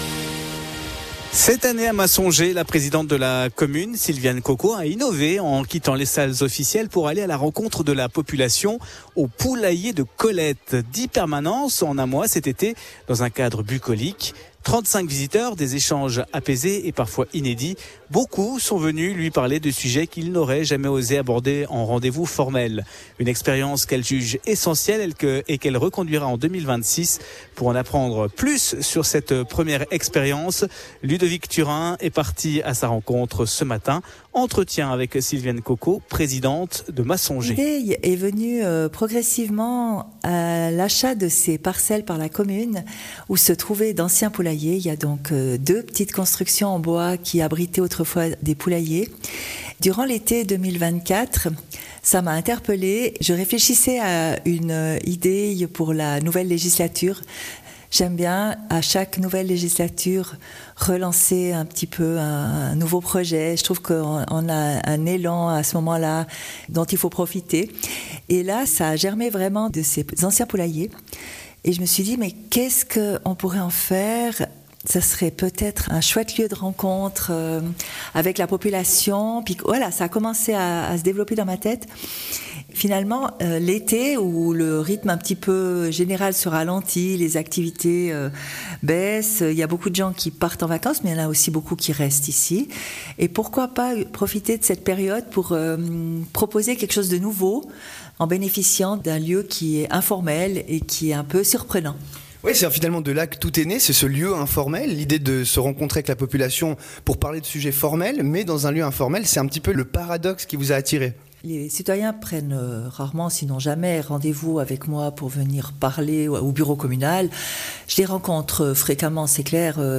Intervenant(e) : Sylviane Coquoz, présidente de Massongex